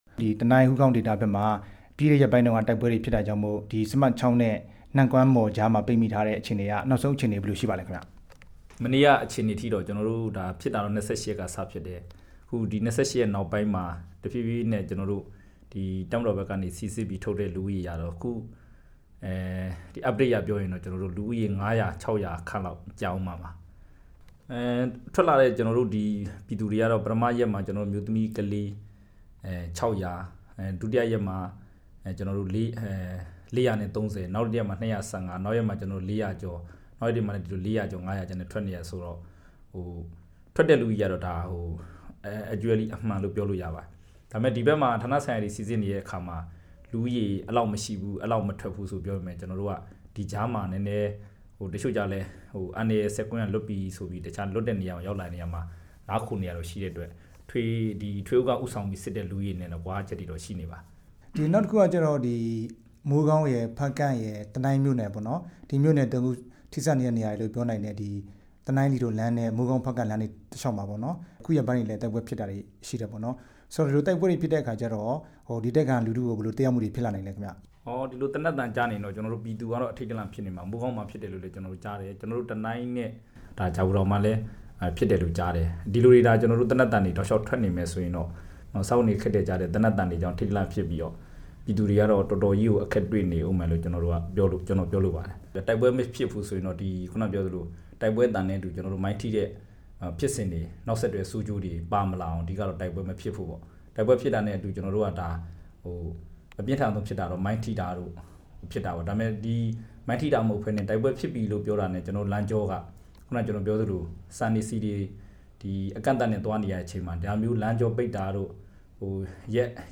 တိုက်ပွဲတွေကြောင့် ဒေသခံတွေ ကြုံတွေ့နေရတဲ့ အခြေအနေ မေးမြန်းချက်